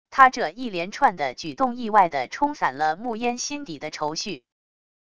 他这一连串的举动意外的冲散了暮烟心底的愁绪wav音频生成系统WAV Audio Player